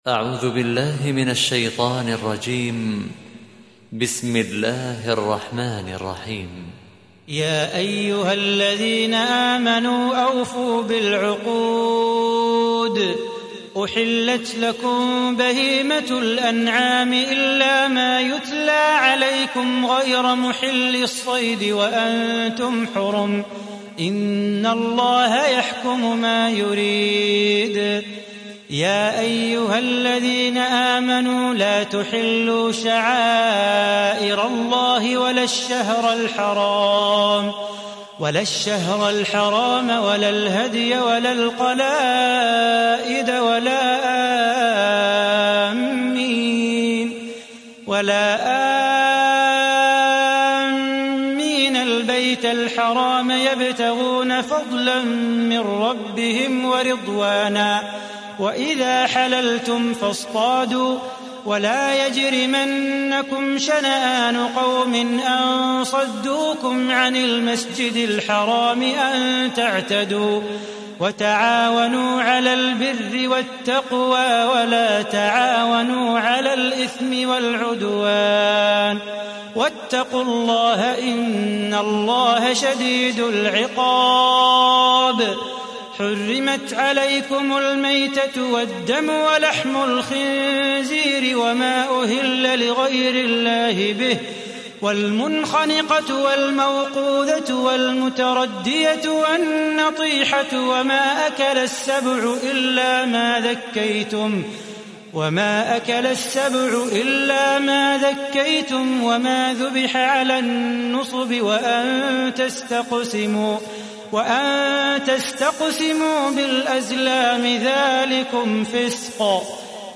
موقع نور القرأن | القران الكريم بصوت الشيخ صلاح بو خاطر
هو ذو صوت عذب تطرب له الاذن والقلب يخشع به